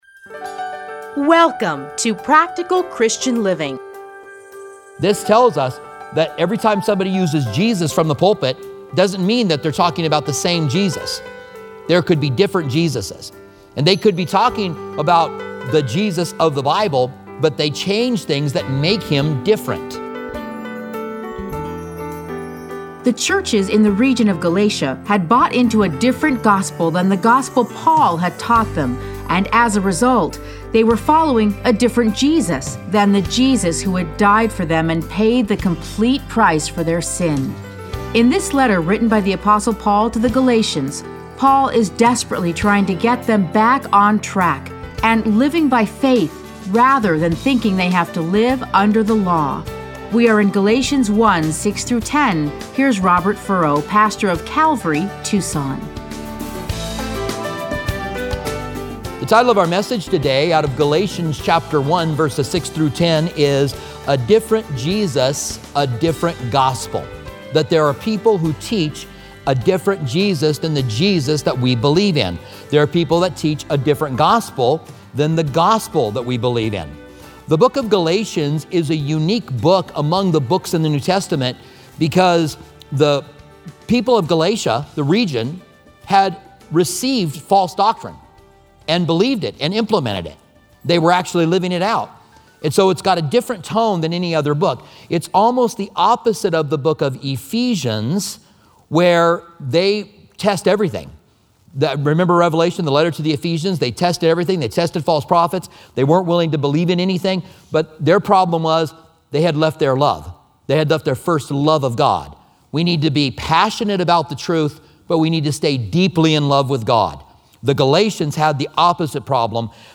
teachings are edited into 30-minute radio programs titled Practical Christian Living. Listen to a teaching from Galatians 1:6-10.